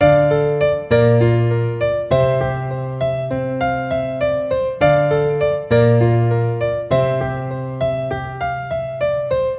原声钢琴 1100bpm
描述：RB/POP的D小调钢琴循环曲。
Tag: 100 bpm RnB Loops Piano Loops 1.62 MB wav Key : D